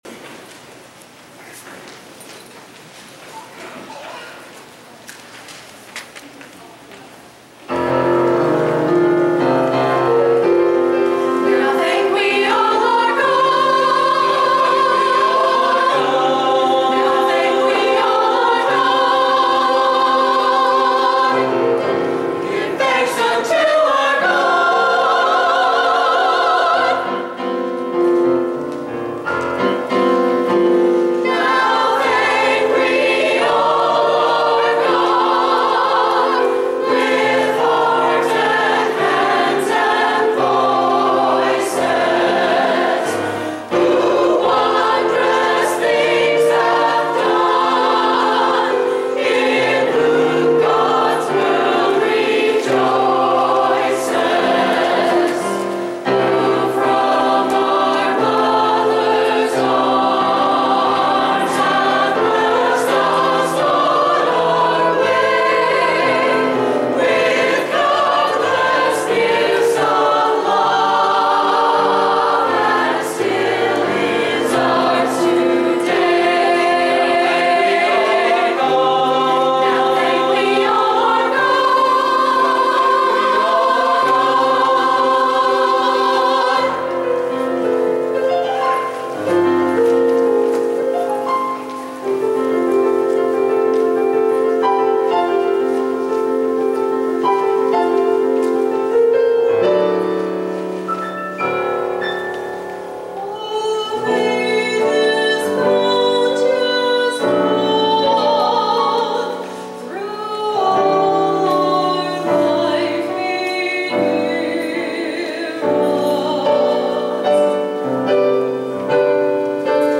Today’s blog is an audio blog, featuring sound clips from the service.
The choir did a rendition of “Now Thank We All Our God”
service-choir-now-thank-we-all-our-god.mp3